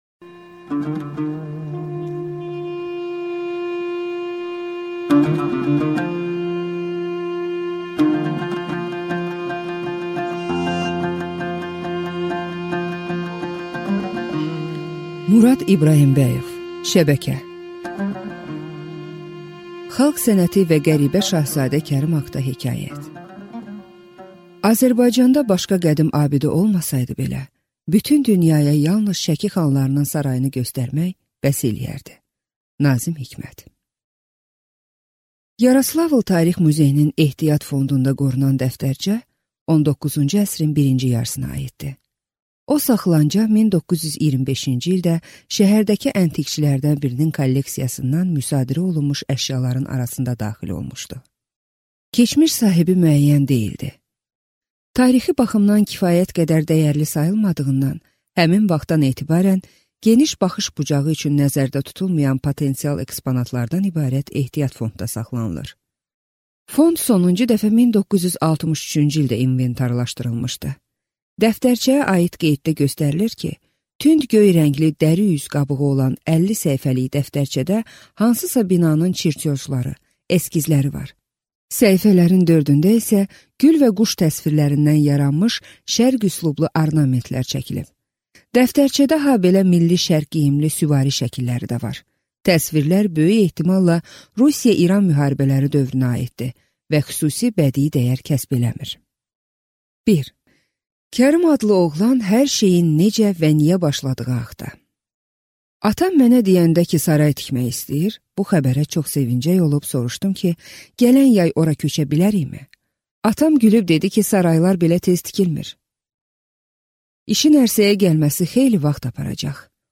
Аудиокнига Şəbəkə | Библиотека аудиокниг